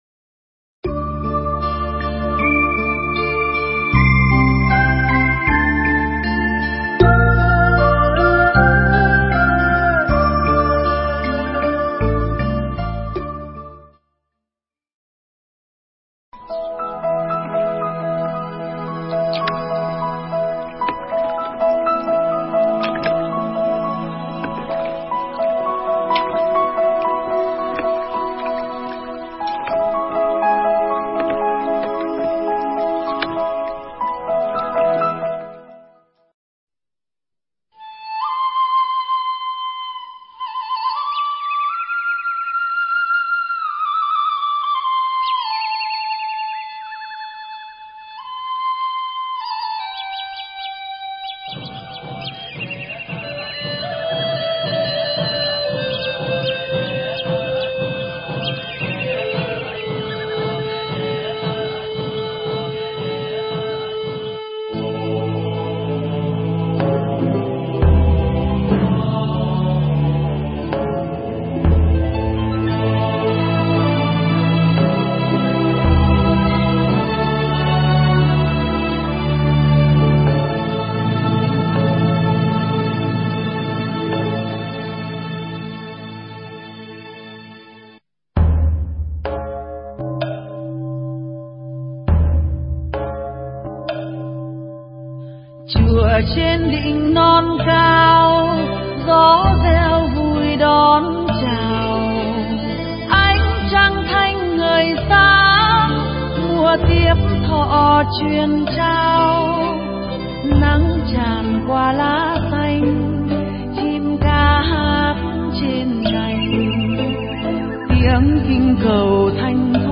Nghe Mp3 thuyết pháp Những Người Bạn Pháp